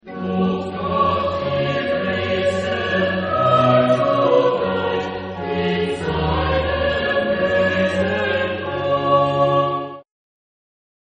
Genre-Style-Forme : Sacré ; Choral
Type de choeur : SATB  (4 voix mixtes )
Instruments : Orgue (1)
Tonalité : la bémol majeur